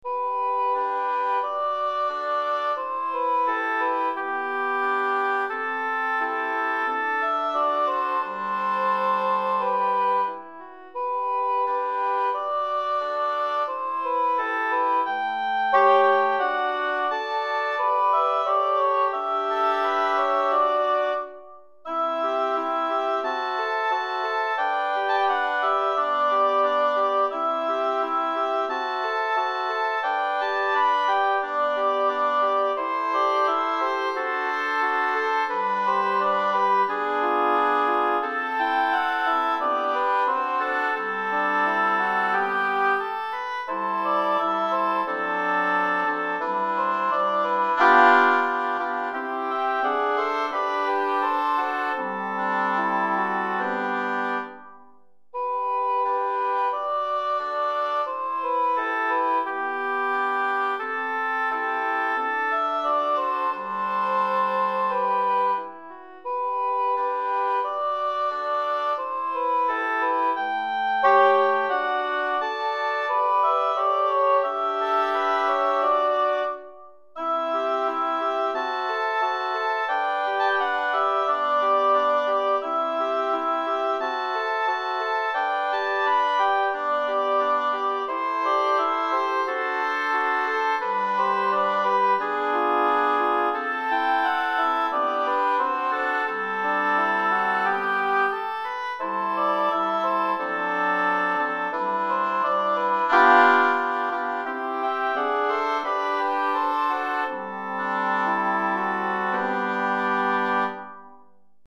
Répertoire pour Hautbois - 3 Hautbois et Cor Anglais